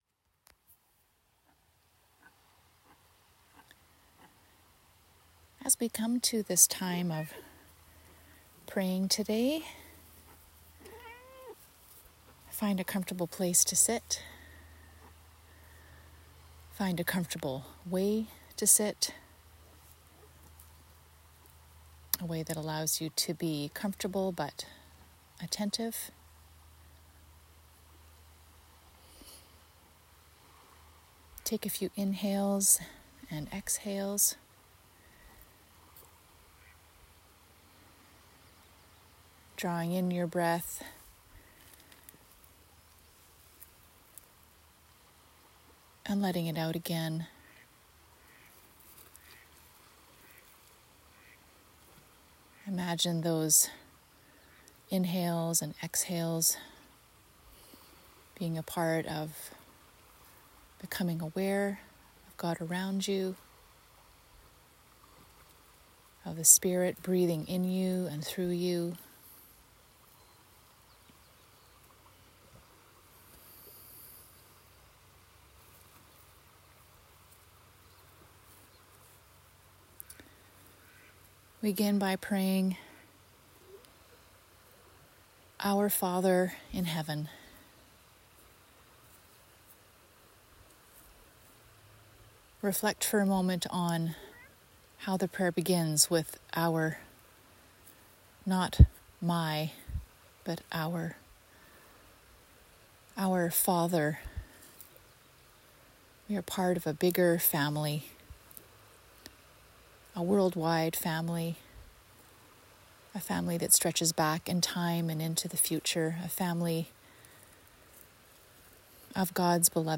slowly reading through the prayer and offering some prompts to guide your personal reflection, leaving some silence in between prompts.